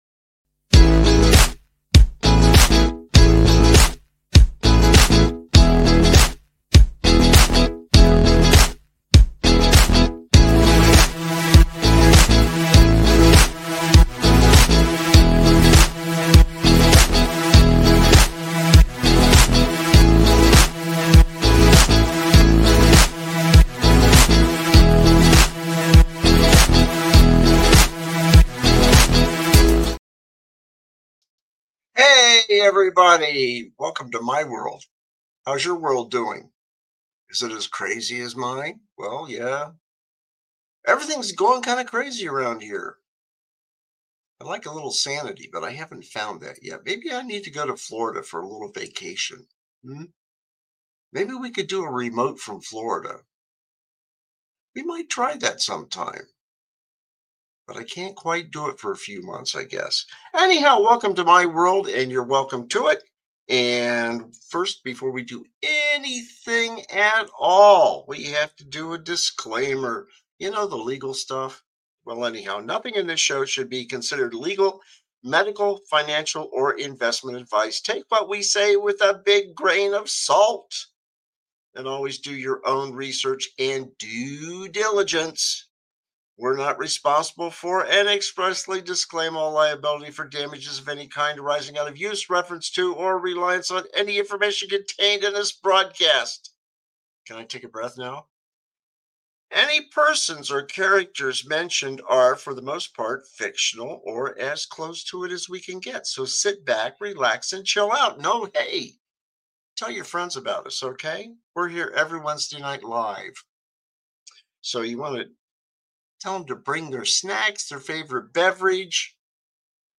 Expect plenty of laughter, thought-provoking discussions, and honest conversations.